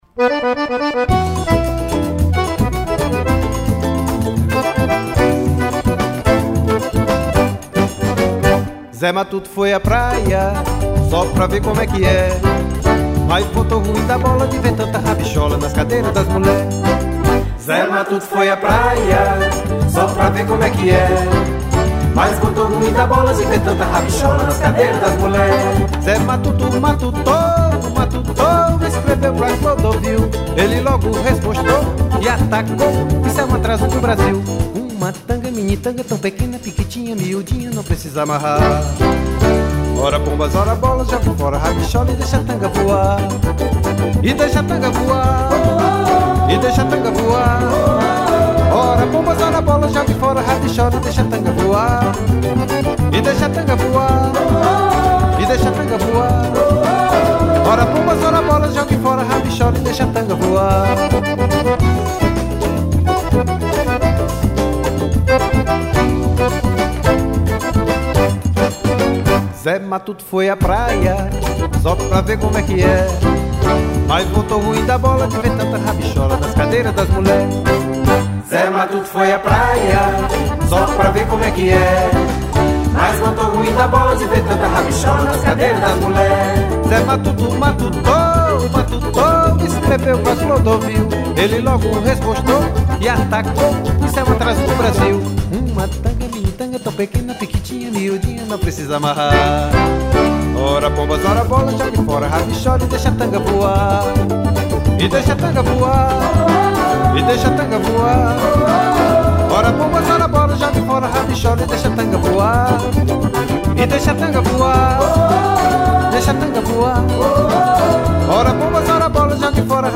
1075   03:15:00   Faixa:     Forró